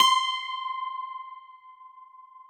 53q-pno18-C4.wav